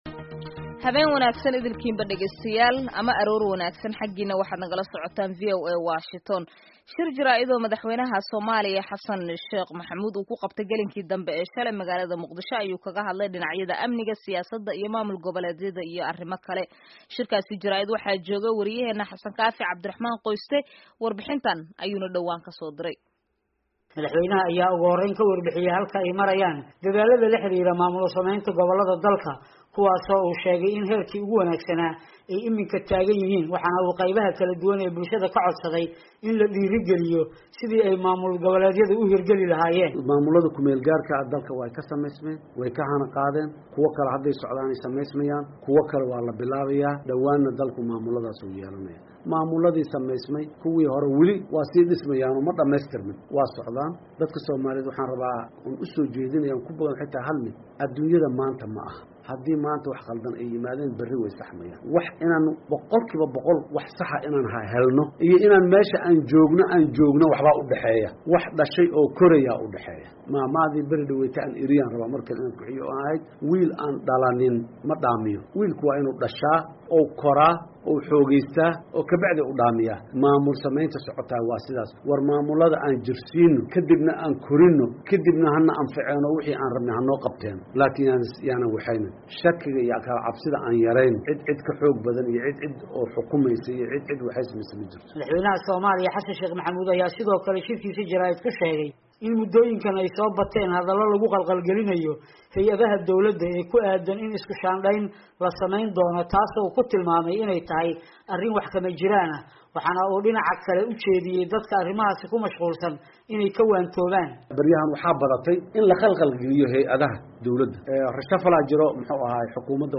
Shir jaarid oo uu madaxweynaha Somalia Xassan Sheikh Maxamuud ku qabtey Muqdihso ayuu ku sheegay inaan isku-shaandheyn lagu samayn doonin xukuumadda. Wuxuu kaloo ka hadlay maamul goboleedyada iyo arrimo kale.
Dhageyso Warbixin iyo Wareysi Isla Socda